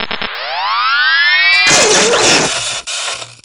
stun.wav